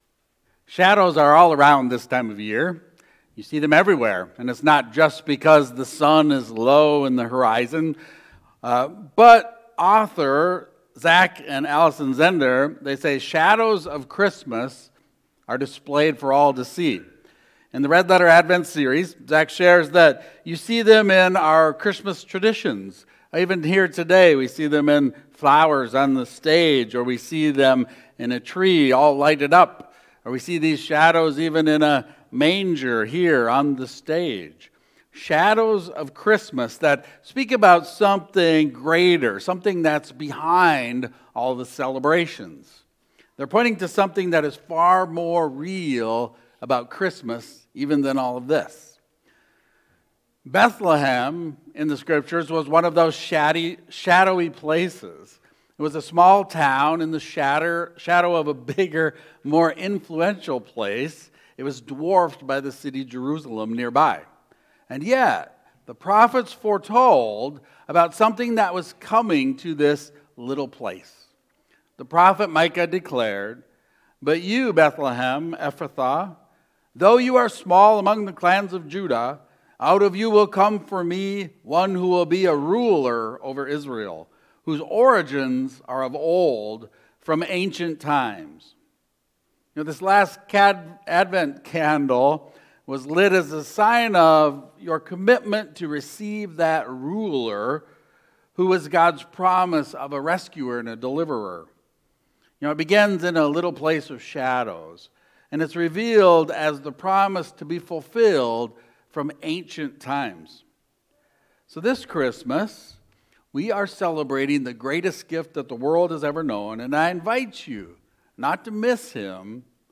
1 Sermon 12.22.2024 - His Gospel is Peace - No Fear of People